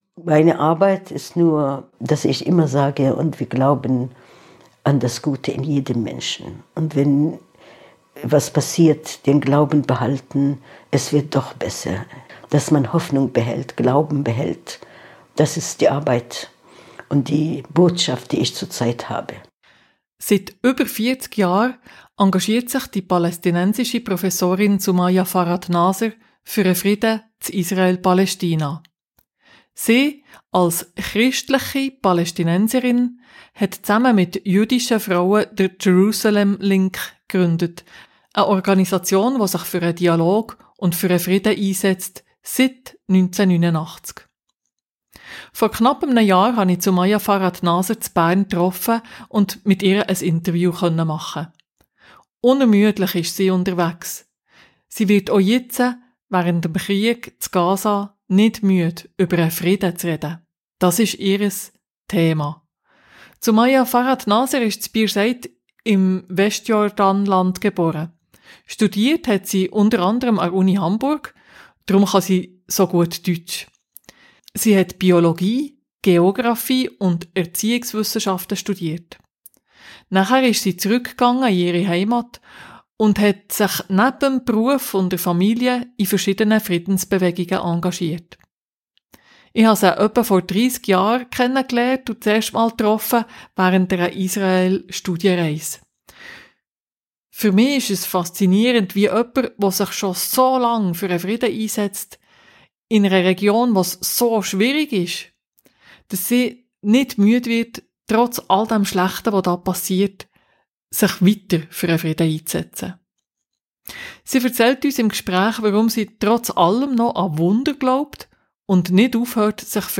Weshalb sie trotz allem noch an Wunder glaubt und nicht aufhört, sich für den Frieden einzusetzen, erzählt sie im Interview.